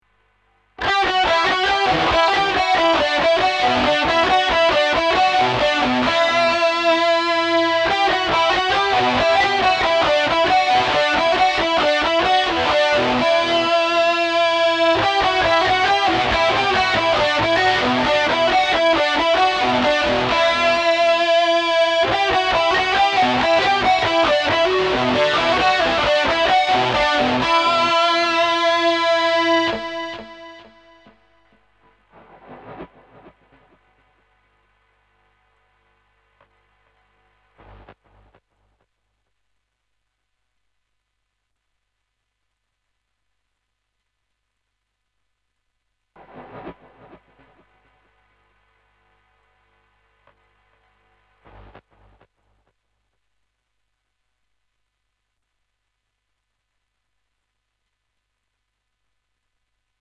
попробовал по памяти подобрать на гитаре вот файл.